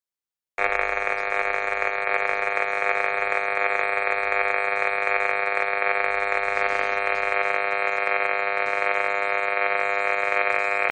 Si bien nuestros oidos no puden escuchar las radiaciones (Microondas) de los artefactos inalámbricos, un analizador de frecuencia es capaz de convertirlas en audio.
Teléfono Inalámbrico
tel_inalambrico.mp3